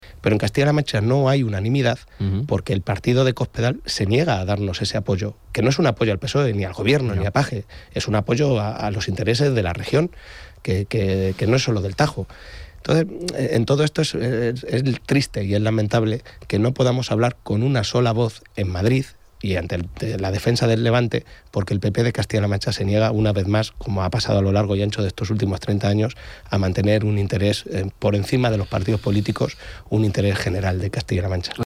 En una entrevista en Onda Cero Castilla-La Mancha
Cortes de audio de la rueda de prensa